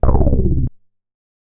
MoogResPlus 010.WAV